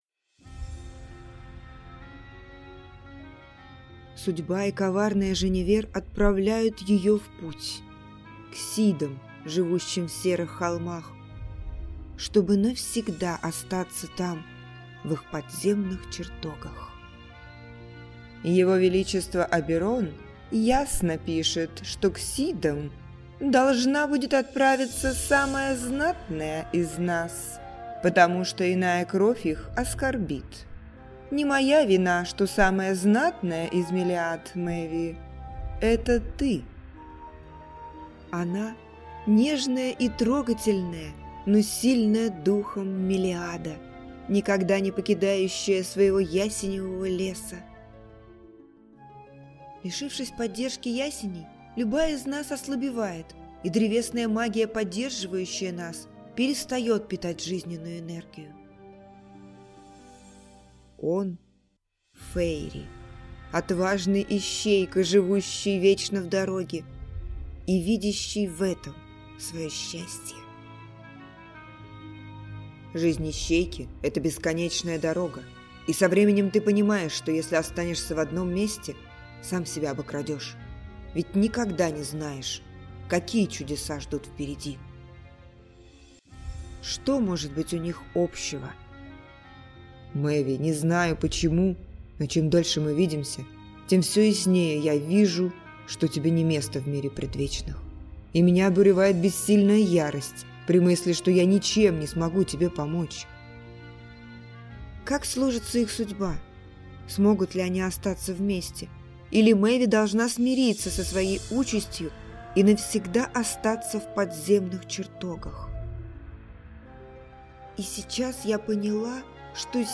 Аудиокнига Избранница Серых холмов | Библиотека аудиокниг